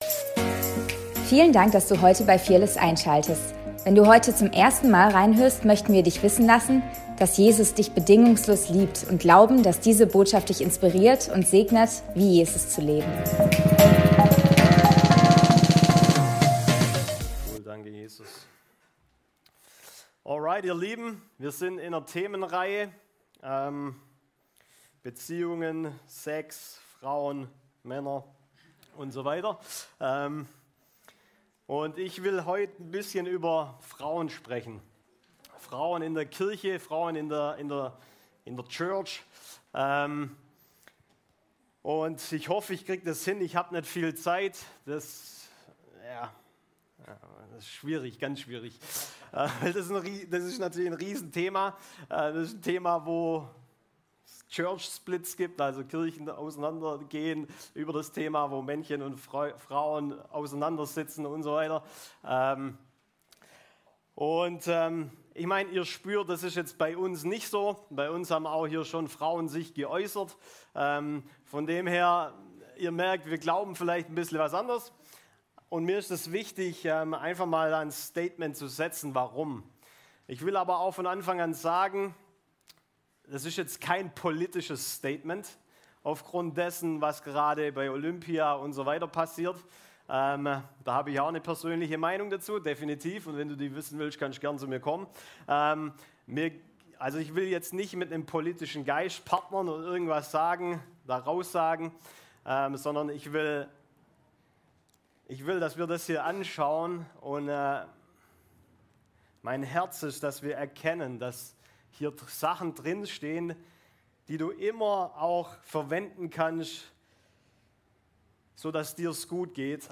Predigt vom 04.08.2024